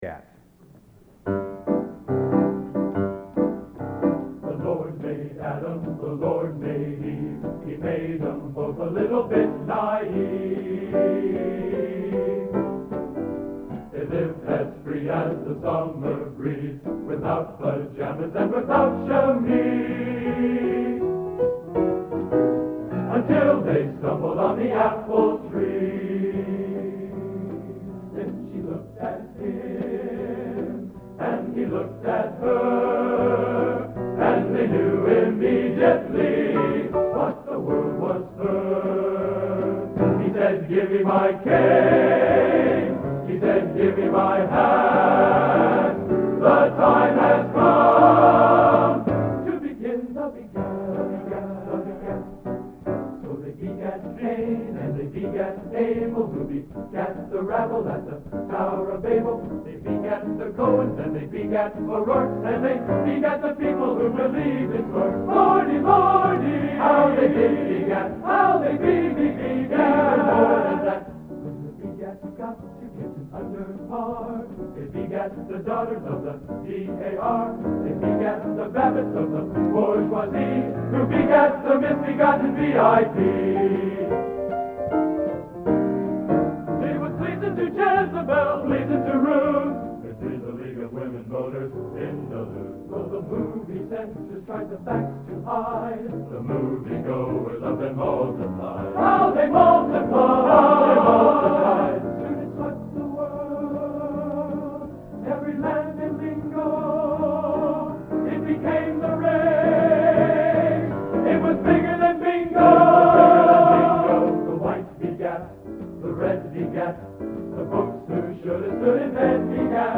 Collection: End of Season, 1983
Location: West Lafayette, Indiana